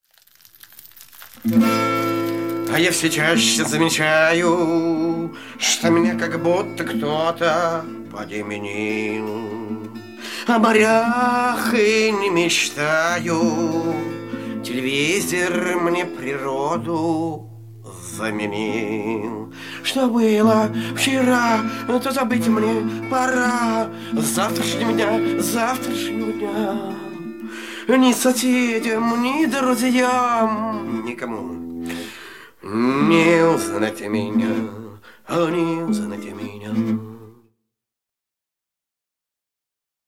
песня из мультфильма.